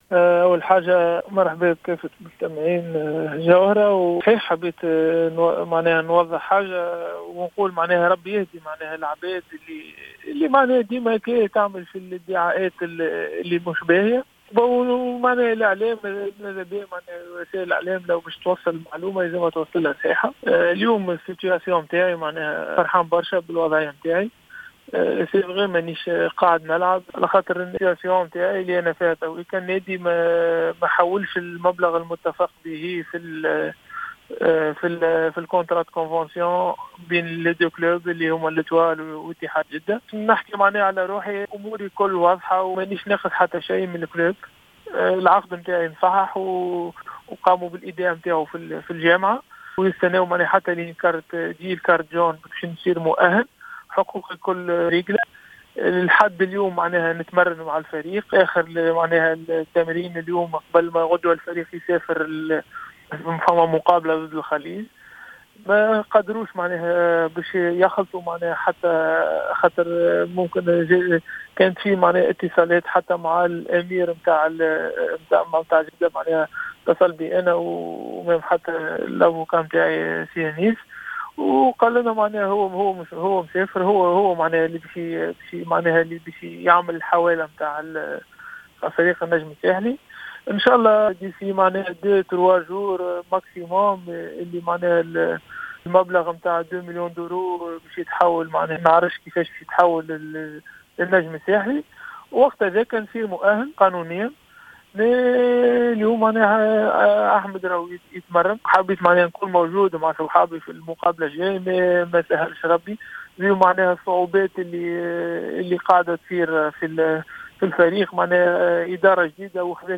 و في مايلي التصريح الكامل لاحمد العكايشي: